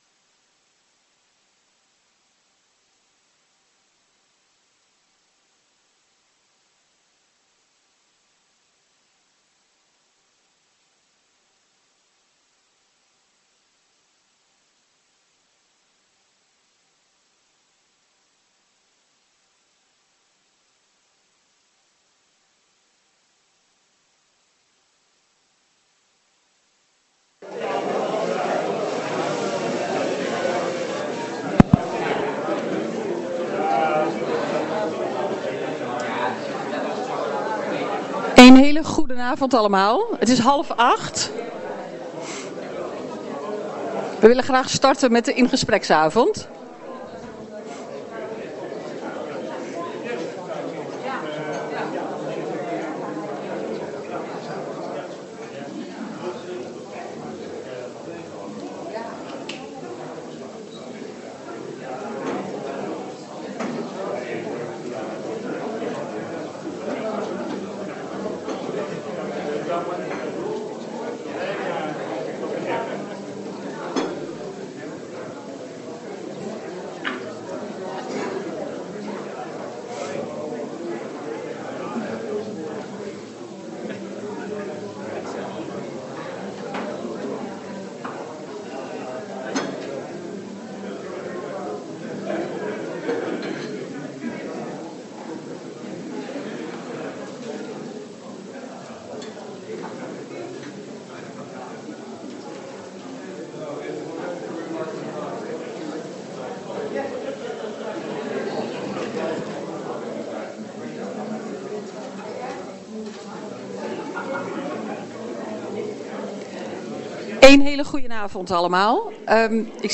Deze bijeenkomst vindt plaats in het gemeentehuis.
Locatie Raadzaal Boxtel Voorzitter Rianne van Esch Toelichting Wilt u deelnemen aan deze In-gespreksavond?